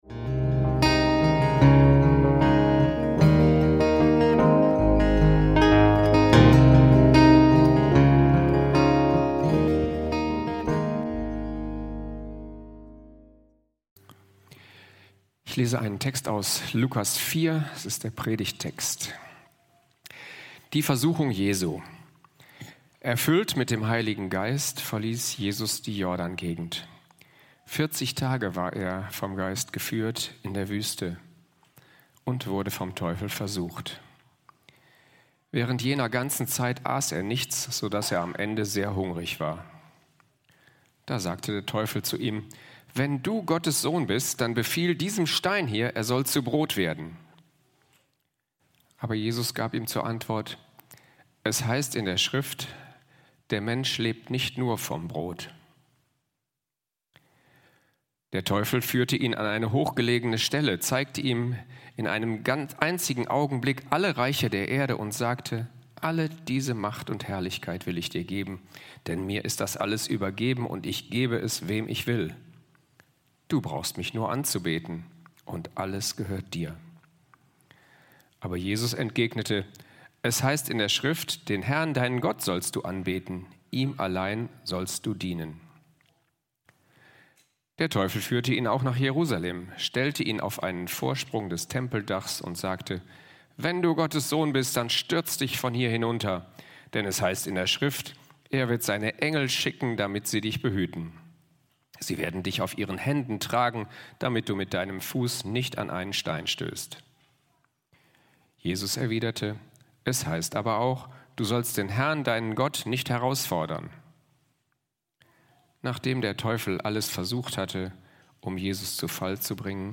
Dem Bösen mit Gottes Hilfe widerstehen - Predigt vom 26.01.2025